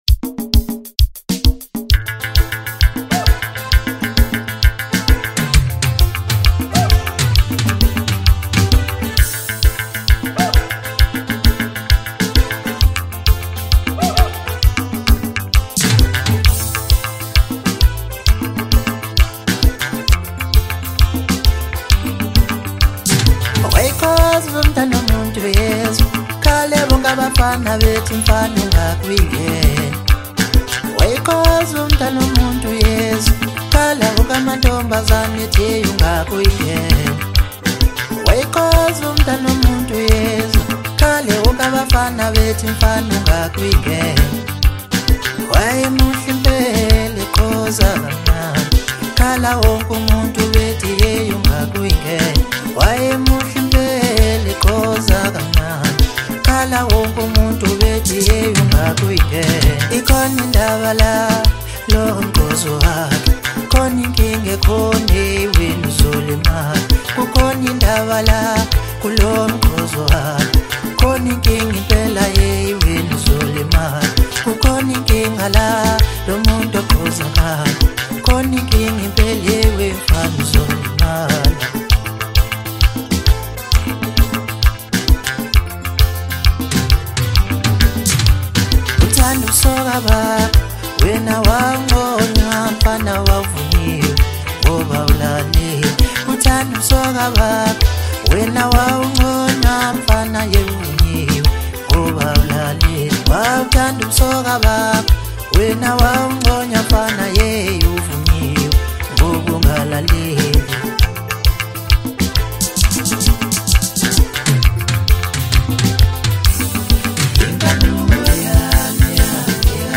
Home » Hip Hop » DJ Mix » Maskandi